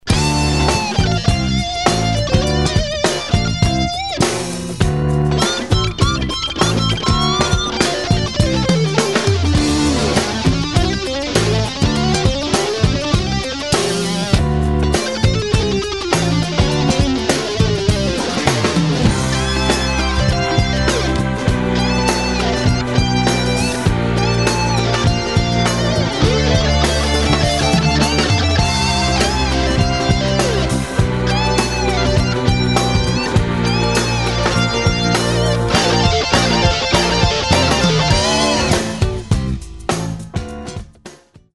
Zvlastny zvuk nalepeny na obe usi posluchaca, ale znie to zaujimavo... A celkom slusne solo okrem toho :)